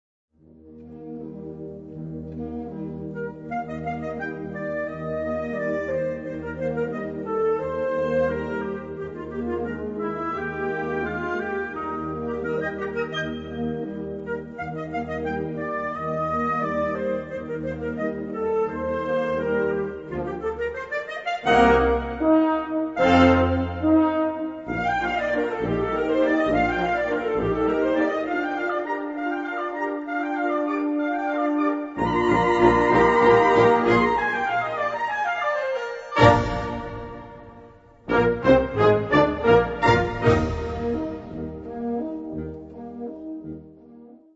Gattung: Walzer
Besetzung: Blasorchester